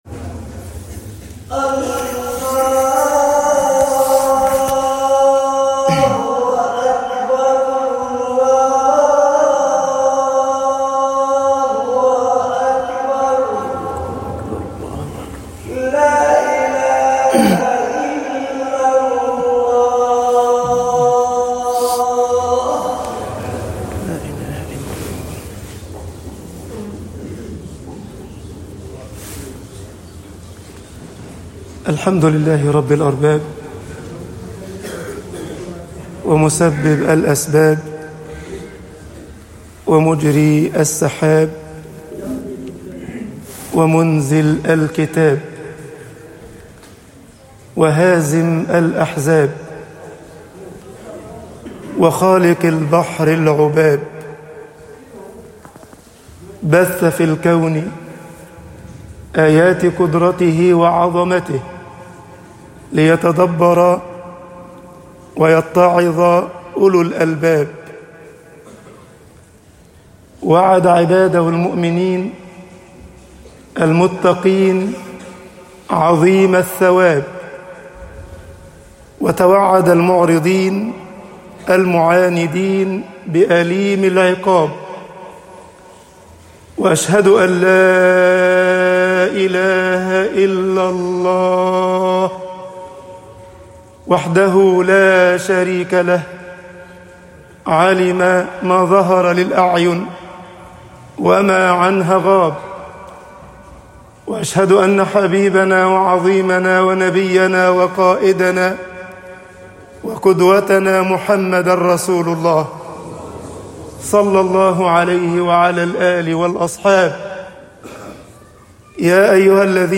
خطب الجمعة - مصر مَوعِظةُ الموت طباعة البريد الإلكتروني التفاصيل كتب بواسطة